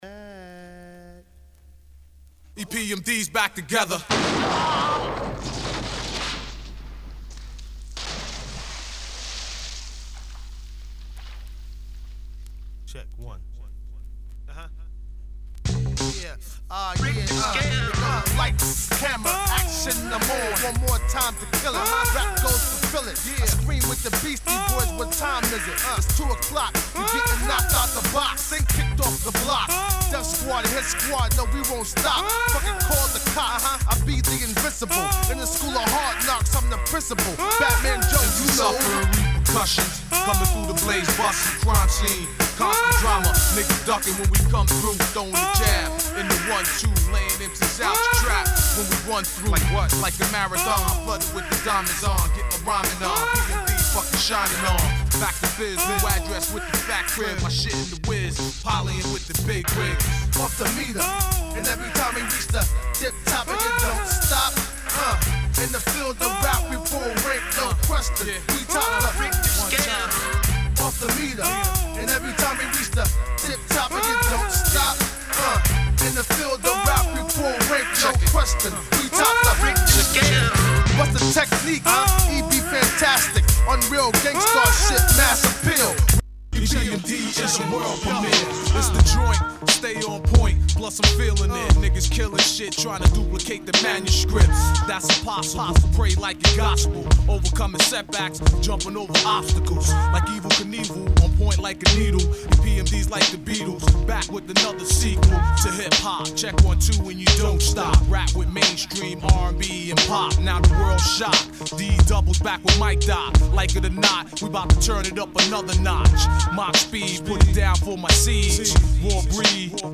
Hiphop-Breakbeat